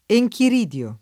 vai all'elenco alfabetico delle voci ingrandisci il carattere 100% rimpicciolisci il carattere stampa invia tramite posta elettronica codividi su Facebook enchiridio [ e j kir & d L o ] s. m.; pl. ‑di (raro, alla lat., -dii )